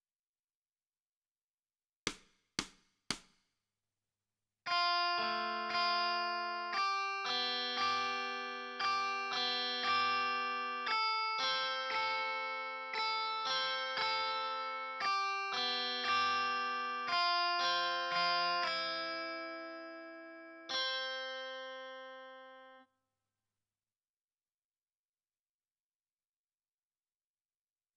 ※曲の中には、無音部分が入っていることもあります。